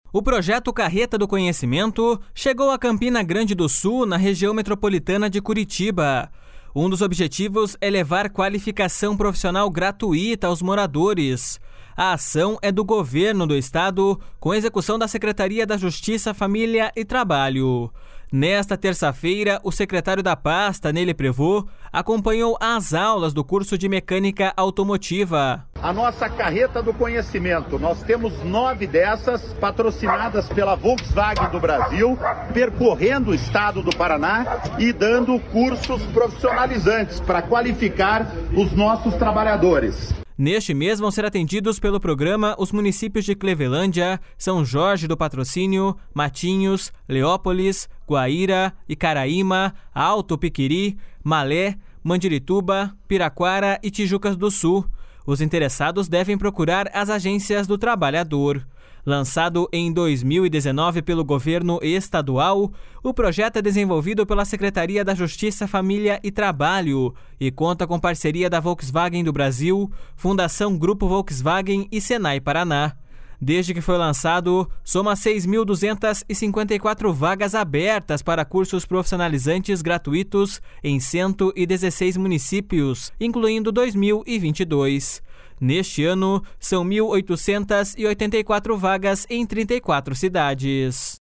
Nesta terça-feira, o secretário da pasta, Ney Leprevost acompanhou as aulas do curso de Mecânica Automotiva.// SONORA NEY LEPREVOST.//